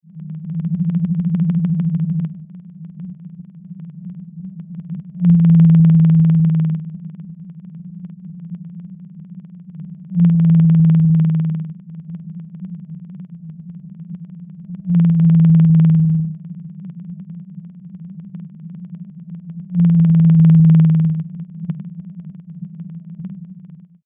A blue whale surfaces.
NOAA Blue Whale
blue-whale.mp3